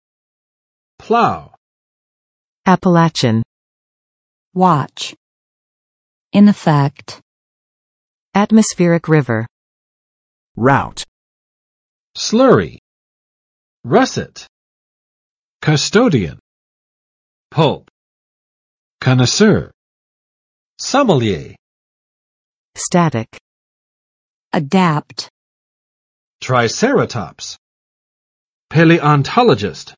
Vocabulary Test - October 26, 2021
[plaʊ] v. 破（浪）前进
plow.mp3